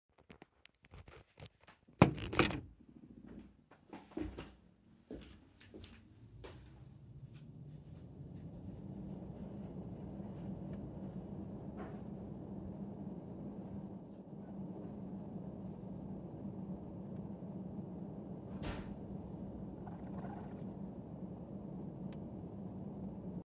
En fonctionnement cette fois-ci.
Bruit split Daikin
On dirait un bruit d'aspiration de paille.
Ce bruit est également présent à l’arrêt du split.
split-daikin.mp3